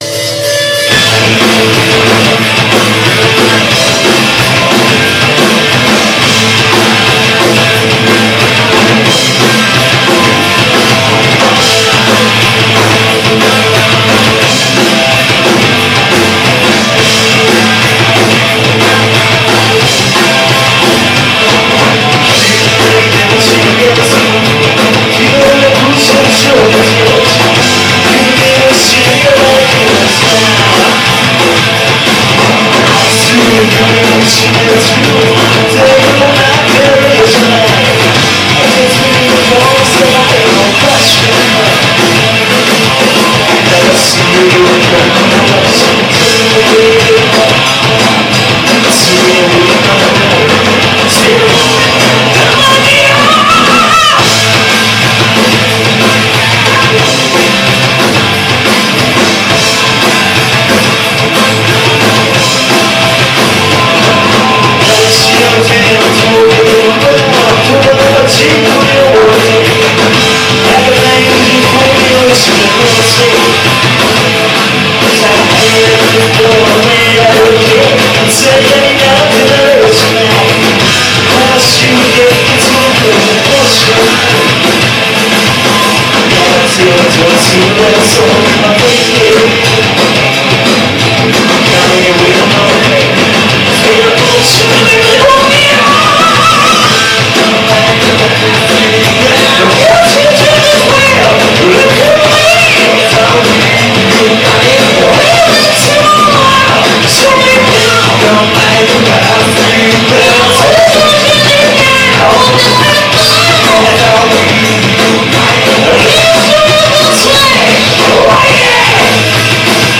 - LIVE VIDEOS-